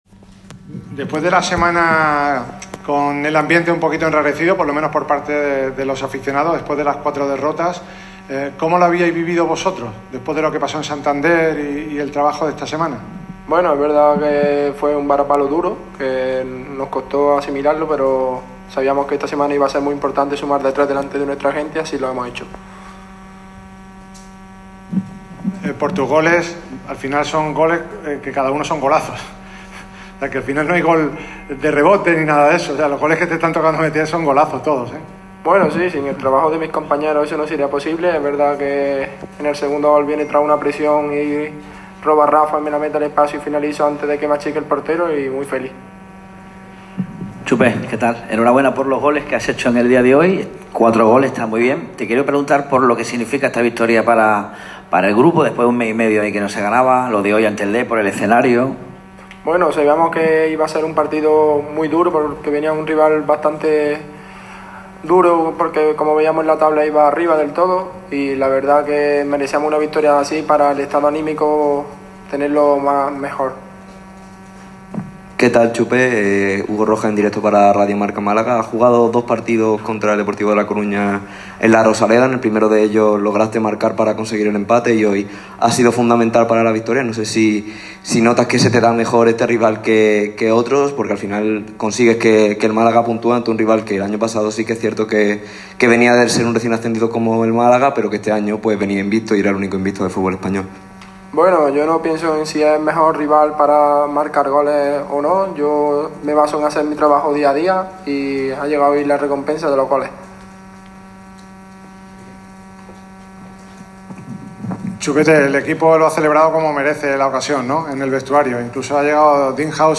El delantero se mostró muy feliz por el triunfo y asegura que era un resultado que necesitaba el vestuario y el equipo. Estas son las declaraciones de Chupete tras su doblete ante el equipo gallego y la tercera victoria del curso para el equipo malaguista.